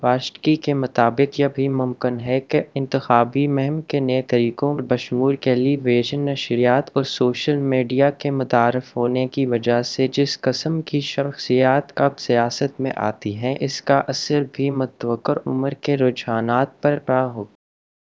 Spoofed_TTS/Speaker_03/107.wav · CSALT/deepfake_detection_dataset_urdu at main